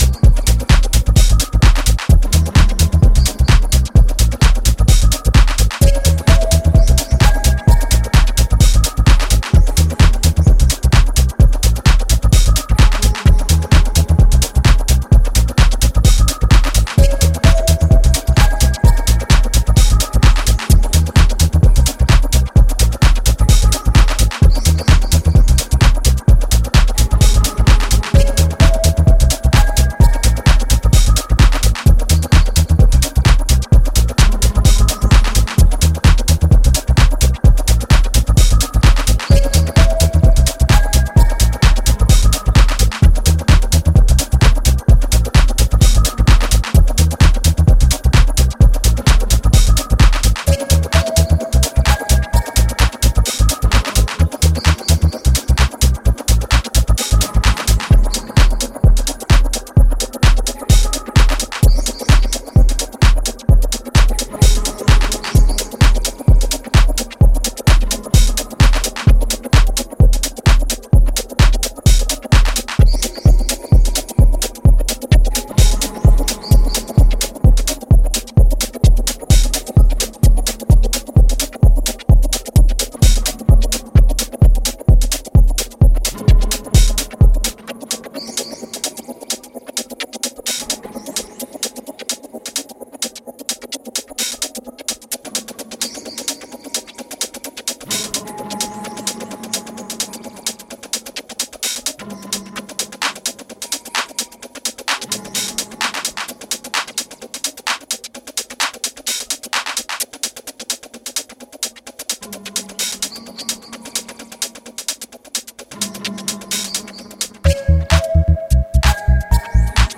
electric three tracker